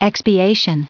1759_expiation.ogg